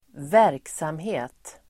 Uttal: [²v'är:ksamhe:t]